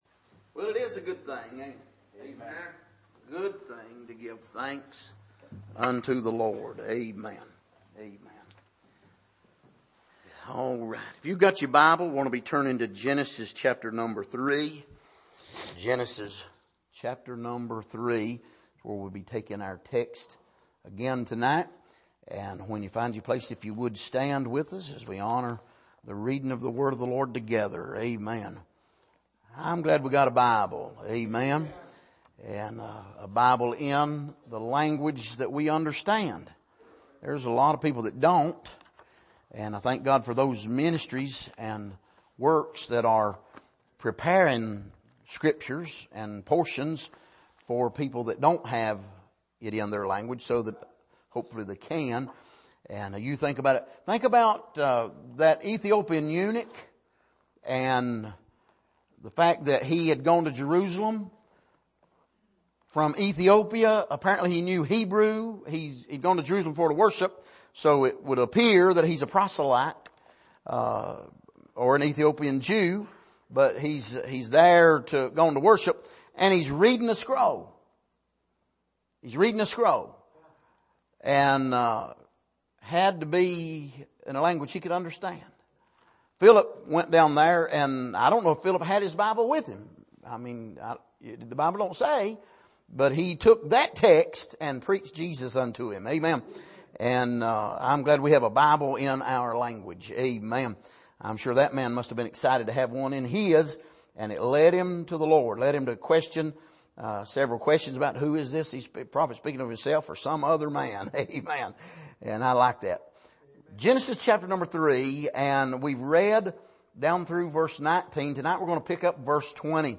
Passage: Genesis 3:20-24 Service: Midweek